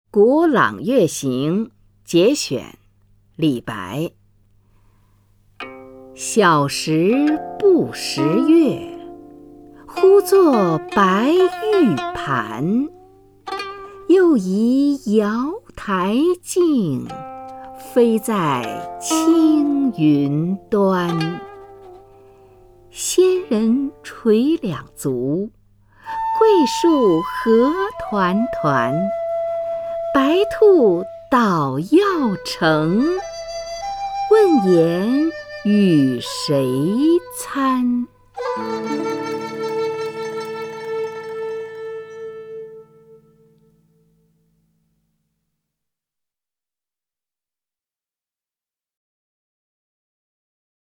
虹云朗诵：《古朗月行》(（唐）李白)
名家朗诵欣赏 虹云 目录